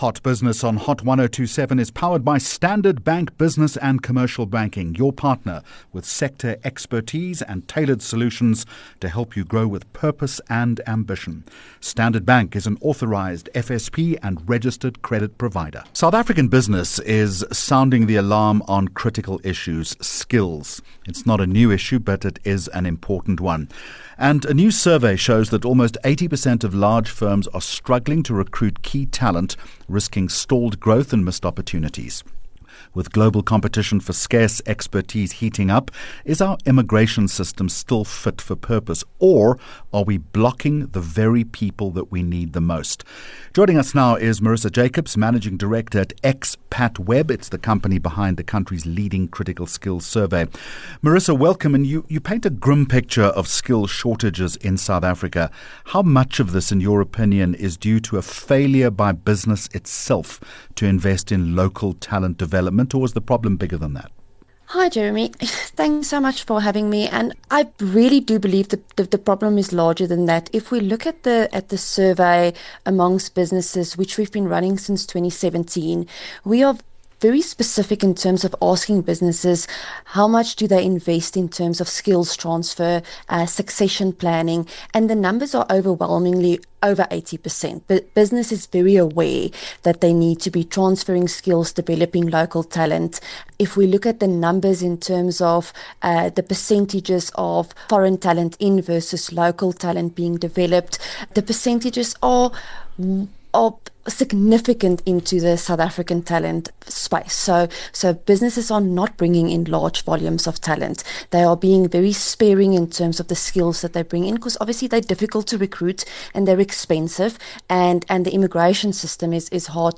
Hot Business Interview